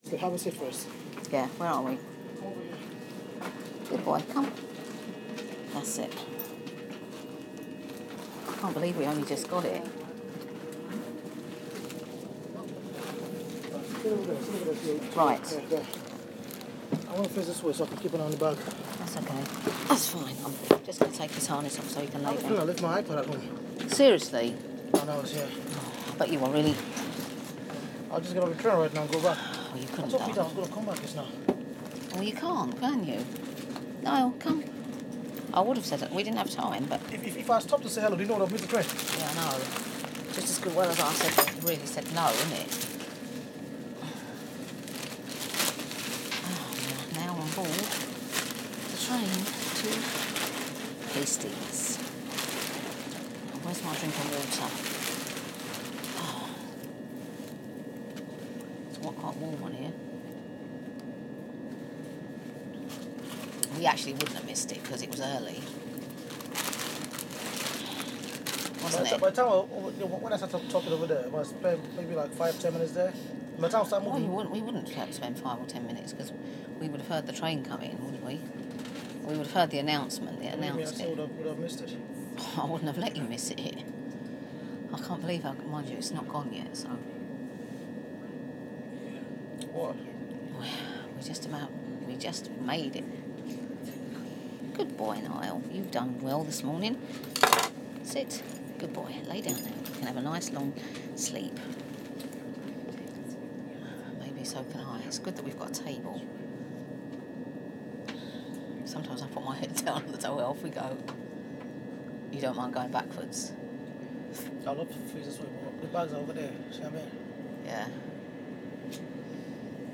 Aboard the Hastings train